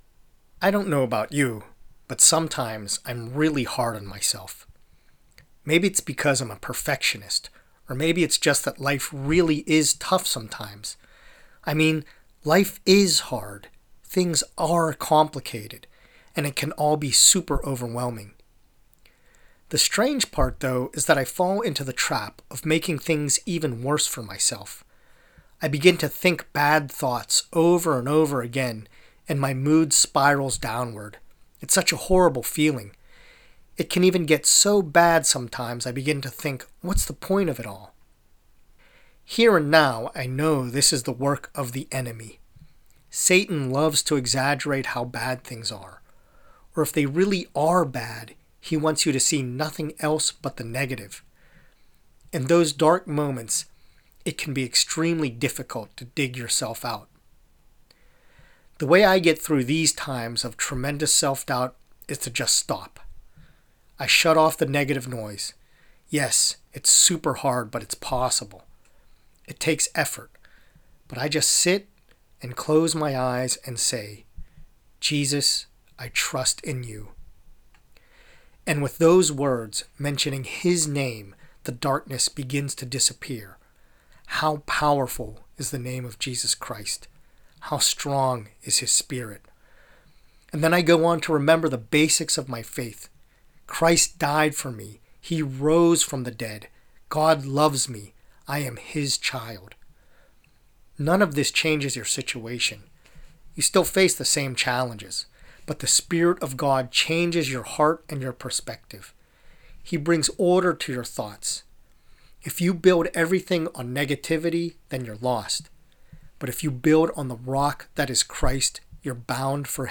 prayer-against-the-lies-you-tell-yourself.mp3